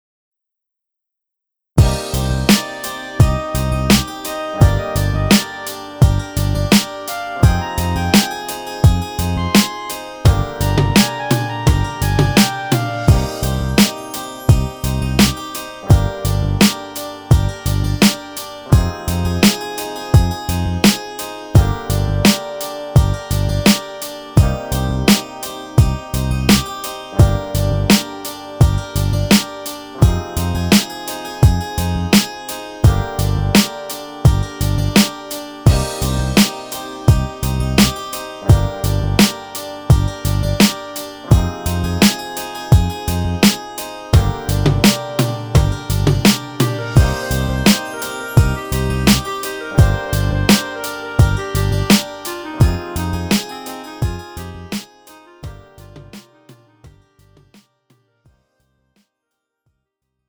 음정 원키 4:10
장르 가요 구분 Pro MR